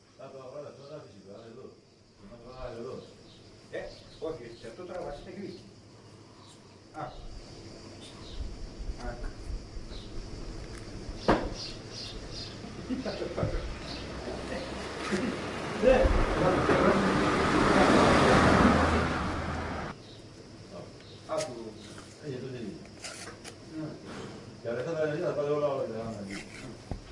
希腊 船只 皮里欧
描述：从比雷埃夫斯到纳克索斯岛（希腊）的一艘旧船中的环境音
标签： 环境 希腊 身临其境 纳克索斯 比雷埃夫斯
声道立体声